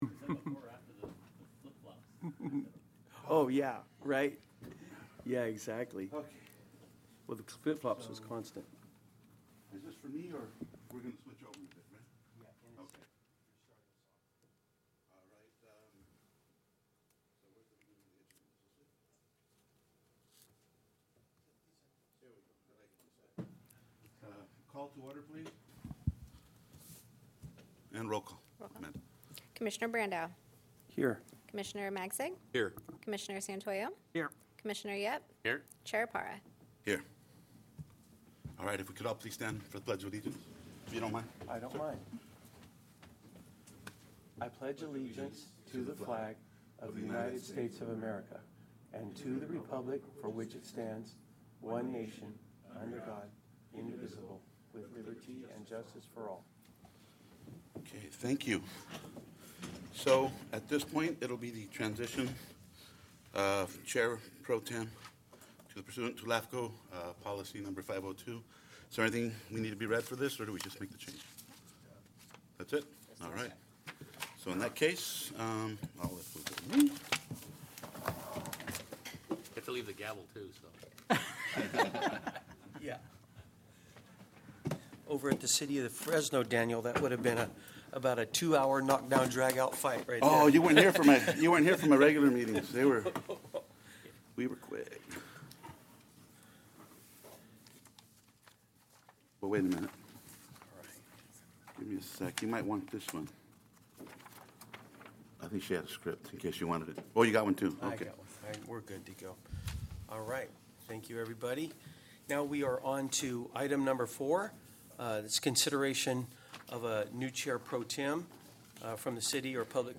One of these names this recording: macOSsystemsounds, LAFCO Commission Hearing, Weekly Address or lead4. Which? LAFCO Commission Hearing